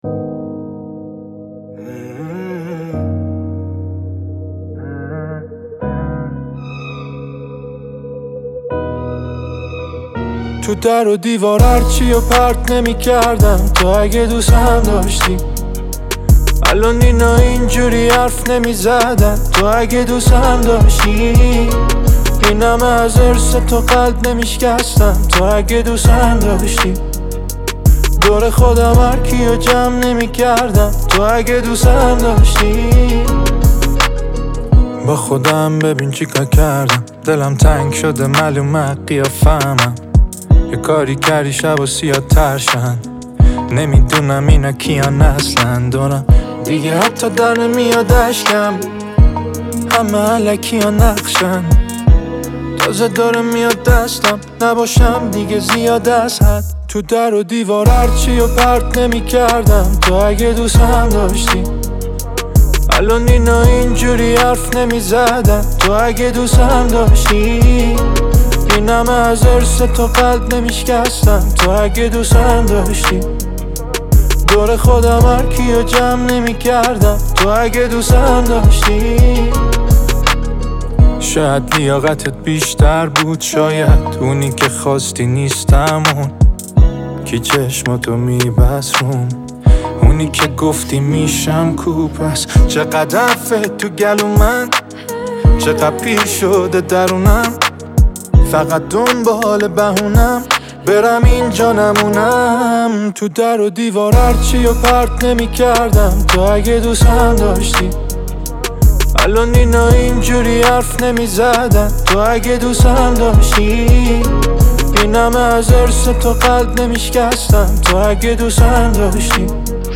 پاپ رپ
موزیک پاپ